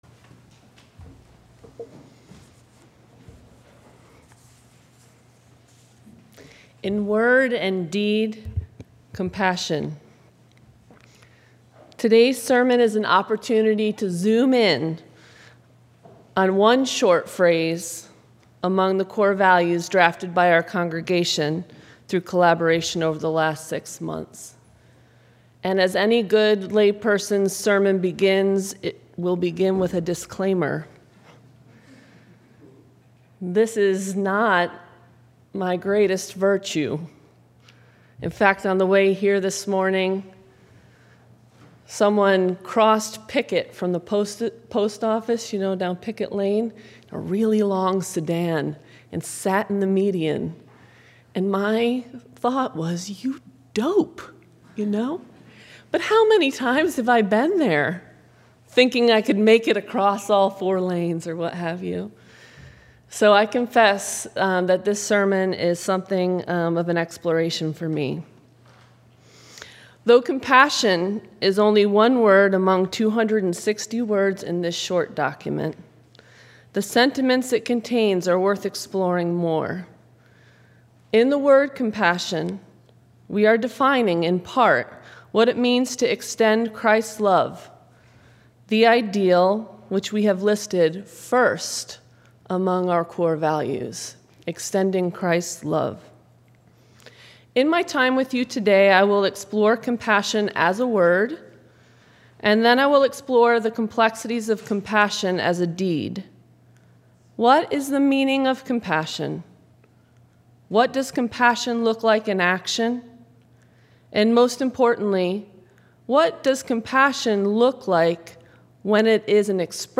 Service Type: Sunday Sermon